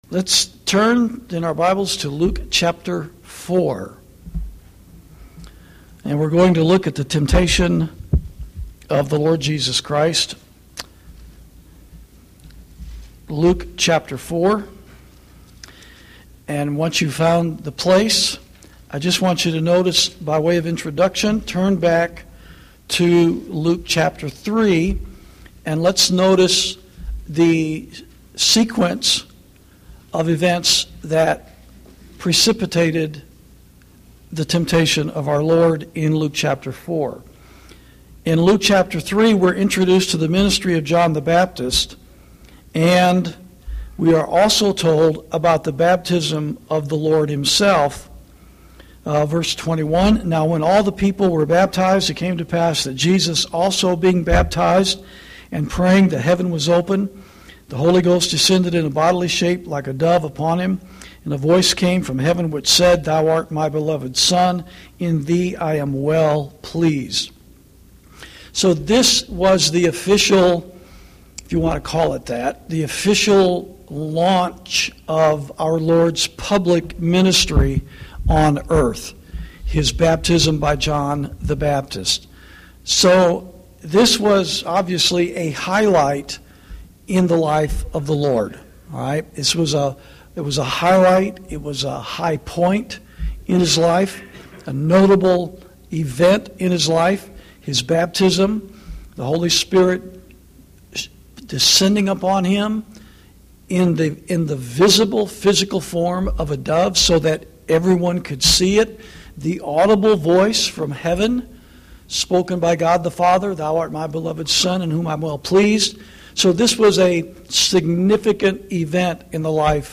Bible Studies > The Temptations Of Christ